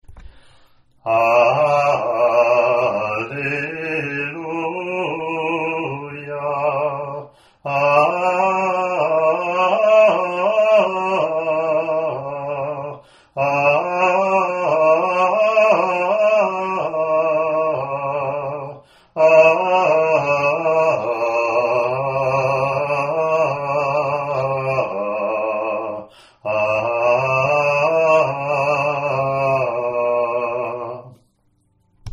Alleluia Acclamation
ot24-alleluia-gm.mp3